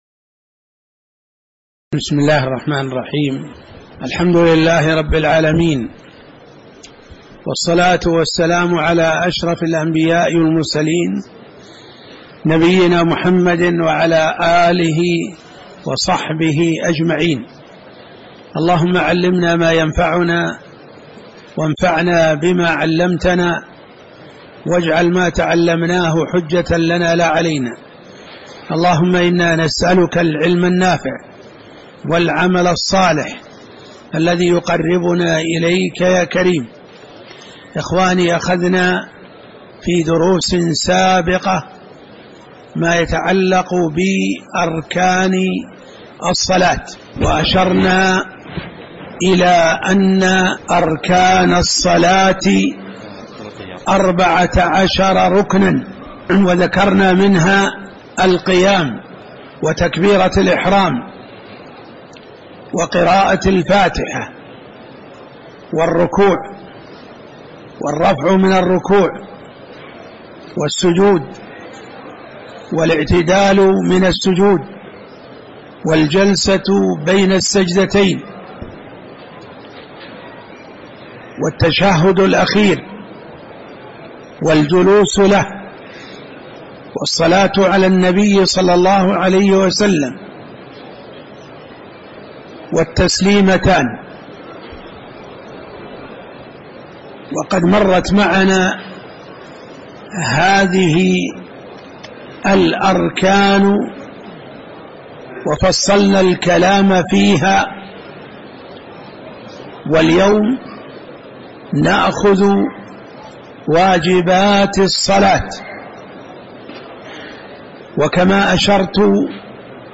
تاريخ النشر ٨ رجب ١٤٣٨ هـ المكان: المسجد النبوي الشيخ